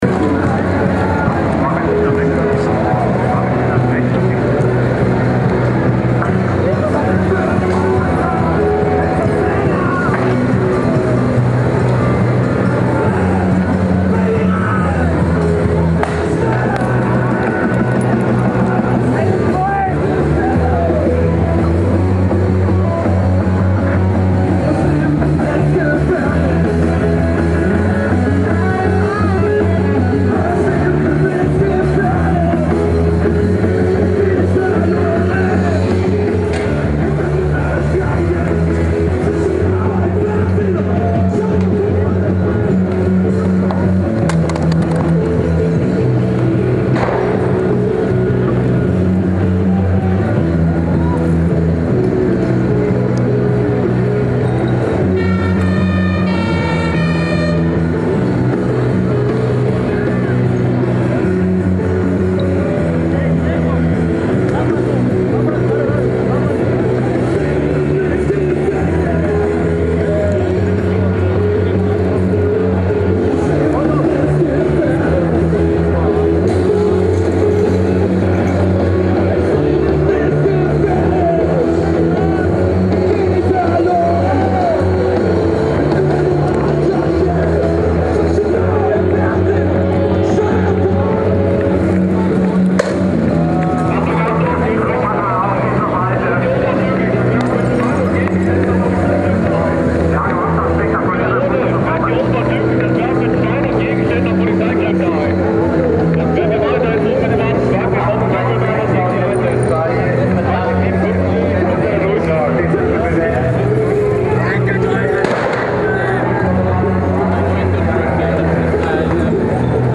for this documentary project I’m searching for a Band played at the 7.7. at about 21 o’clock at this dead end road near “Grüner Jäger”, between “Stresemannstr” and “Neuer Pferdemarkt”.
They didn’t play on a stage, the played on the ground in front of their bus.
Band.mp3